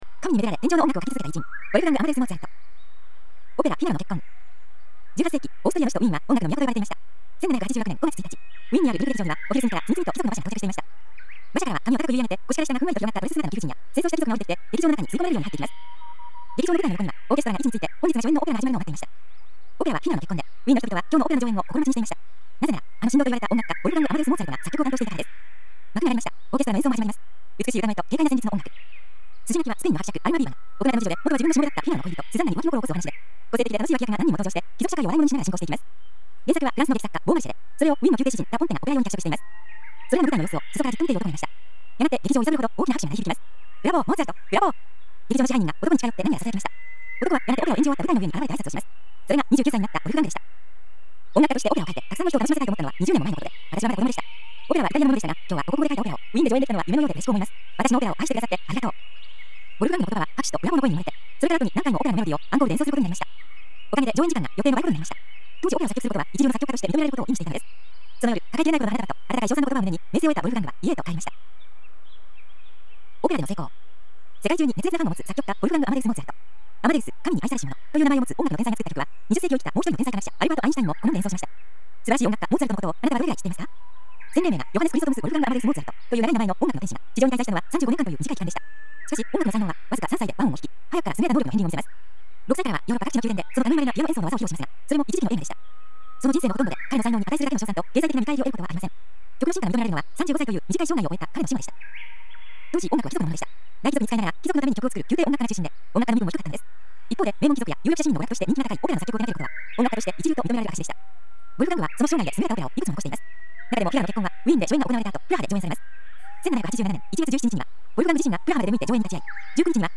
森の中の鳥のさえずりなどの自然音がバックに流れる中、独自のコンテンツや価値ある講演などの音声を、１倍速から無理なく段階的に高速再生し、日々音楽のように楽しく聴くことによって、年齢に関係なく潜在意識を “脳力全開”させていくシステムです
歴史上の偉人たちの話を、わかりやすい文章と穏やかな語りでお伝えする朗読ＣＤです